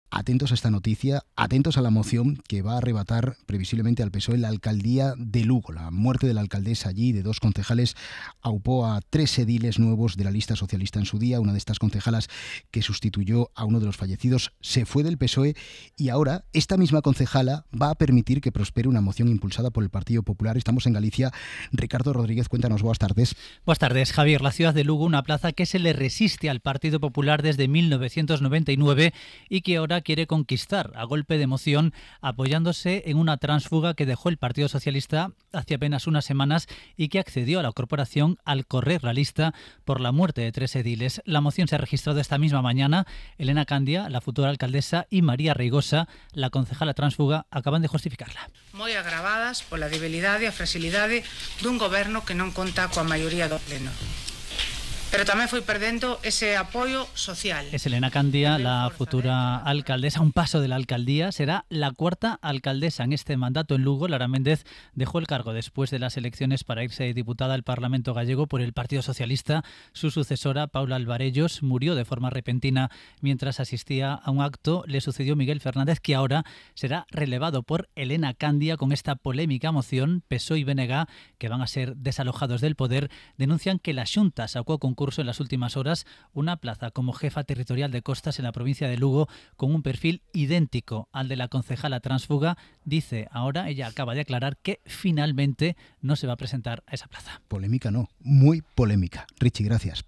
'Hora 14' es el informativo líder del mediodía.